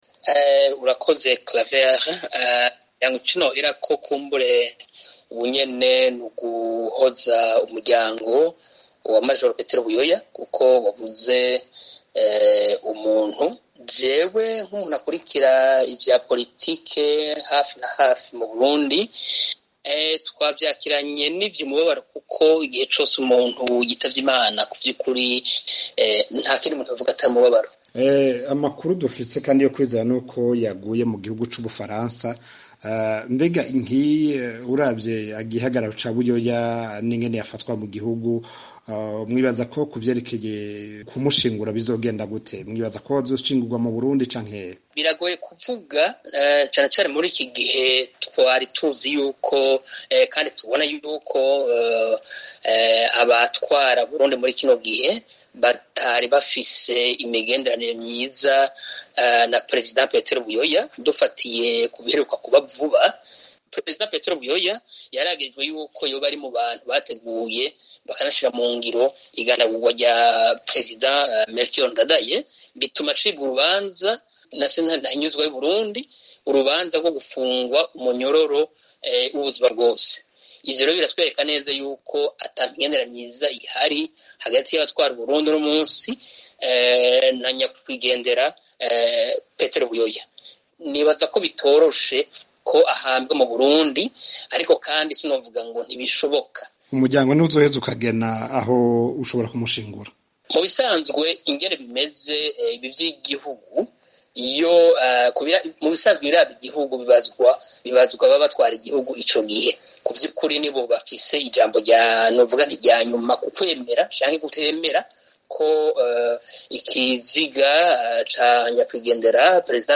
Yavuganye n'ijwi ry'Amerika ari muri Reta zunze Ubumwe za Amerika, muri leta ya Maine, asobanura kw'iragi n'ico abantu bazokwibukira kuri Perezida BUYOYA, hamwe n'igihugu ashobora gushingurwamwo.